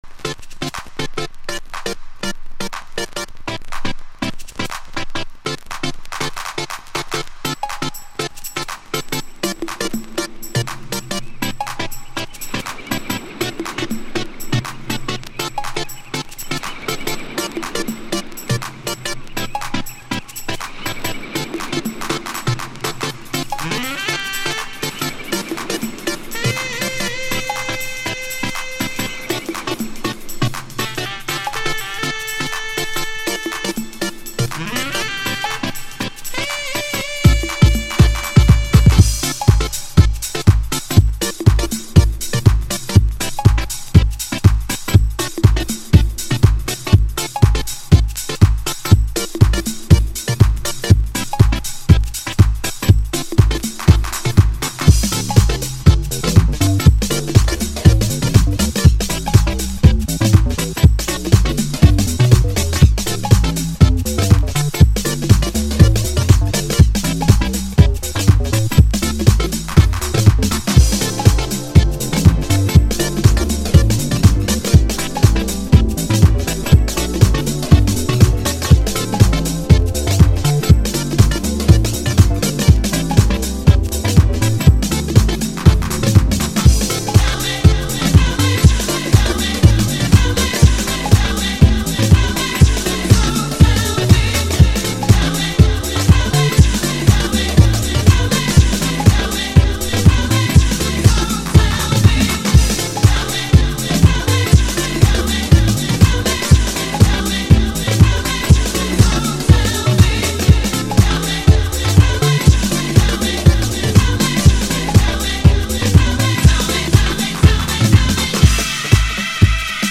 Early House / 90's Techno
US HOUSEの影響化メロディアスな部分を詰め込んで、しっかり粘着ベースでグルーヴするイタロハウスです。